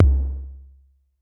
Tr8 Tom 03.wav